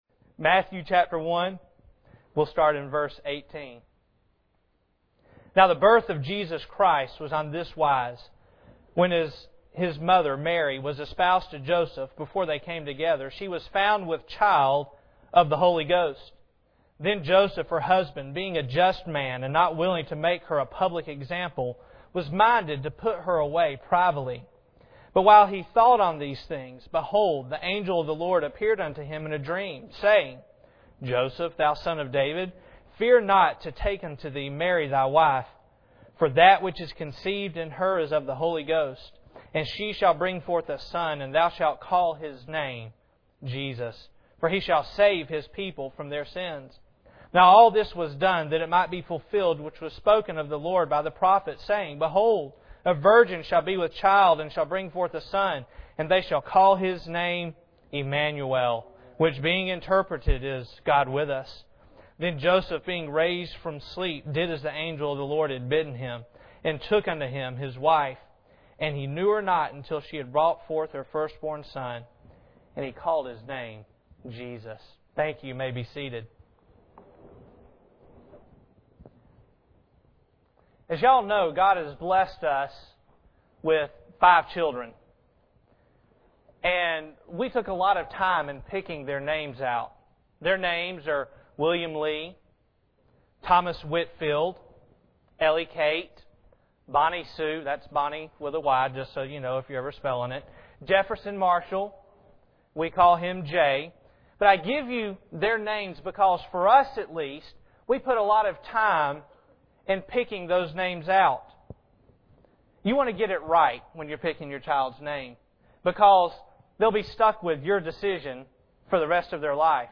Matthew 1:18-25 Service Type: Sunday Morning Bible Text